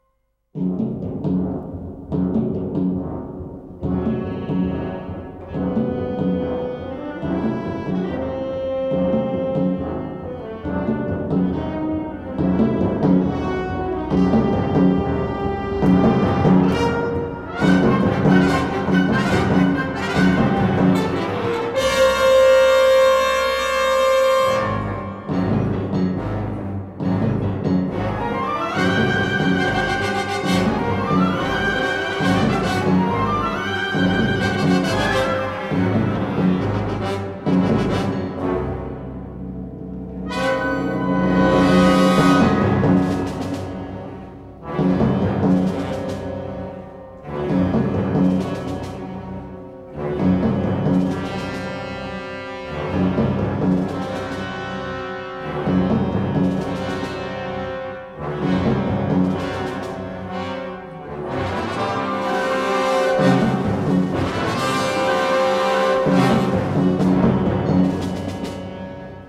symphonic ensemble